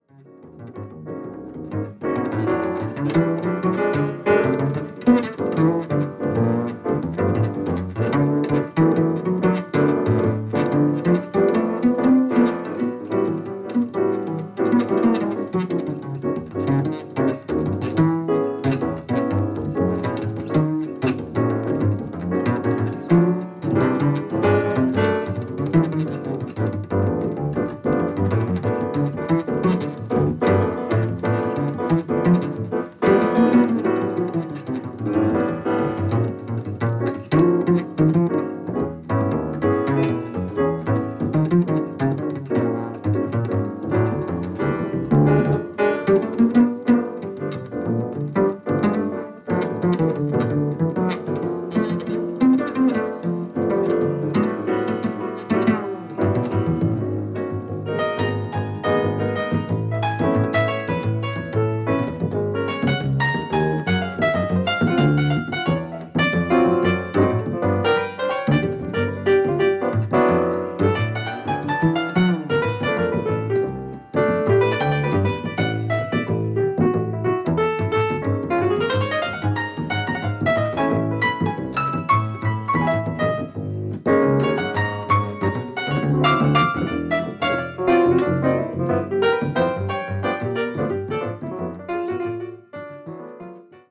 Bass
Piano